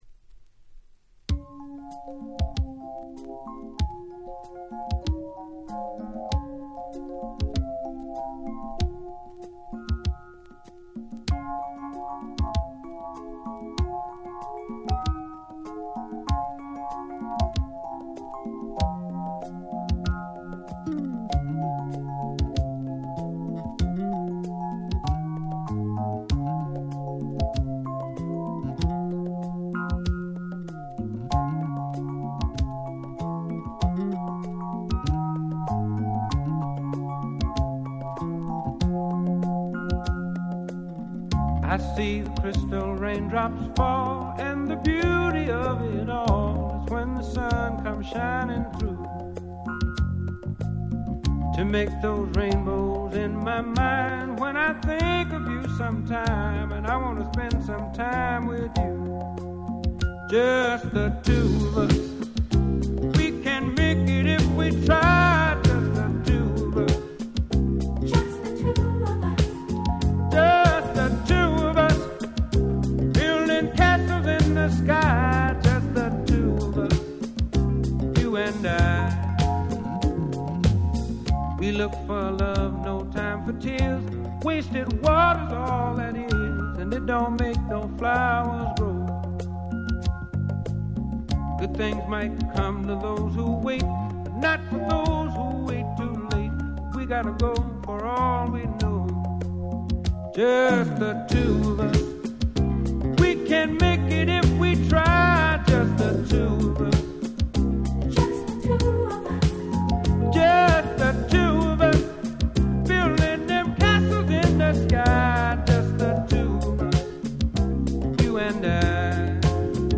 音質もバッチリ！！
Soul ソウル レアグルーヴ